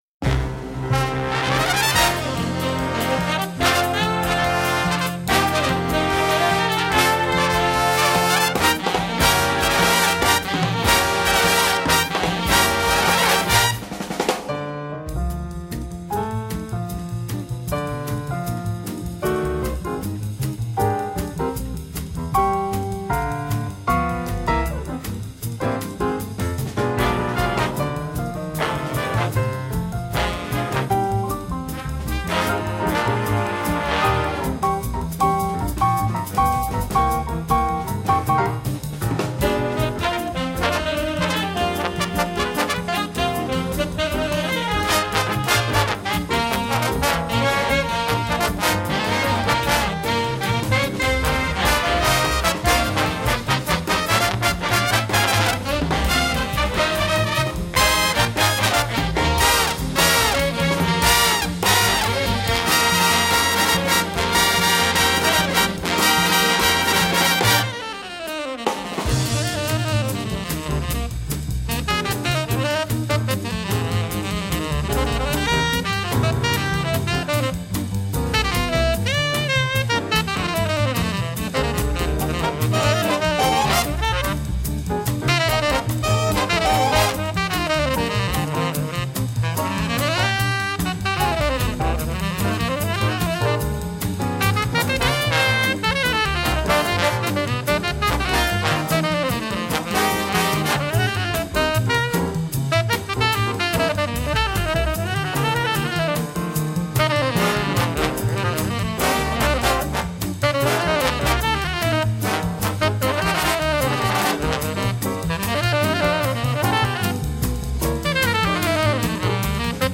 Jazz Ensemble Level